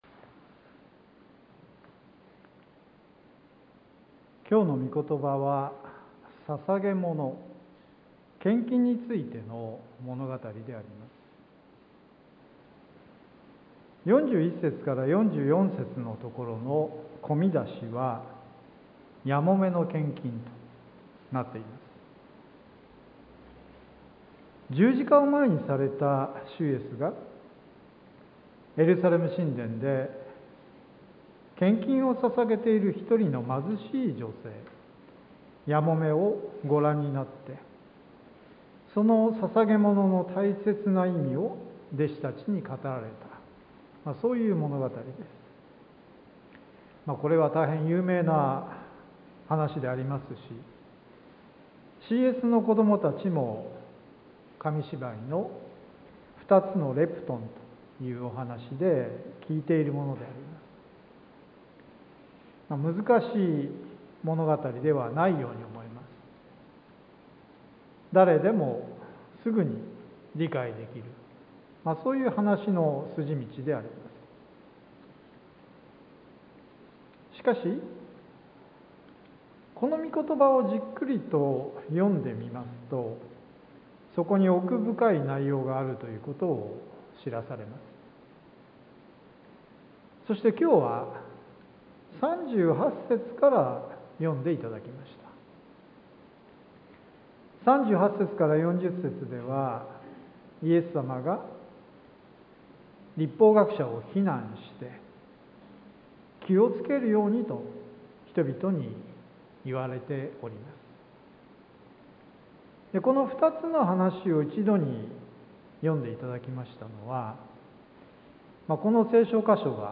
sermon-2022-02-20